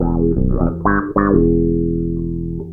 wahbass.mp3